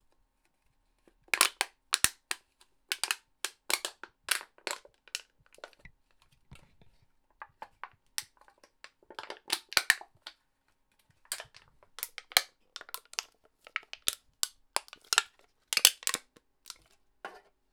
• crashed soda can crunch.wav
Immerse yourself in the distinct, tactile sound of a soda can being crunched.
crashed_soda_can_crunch_yNi.wav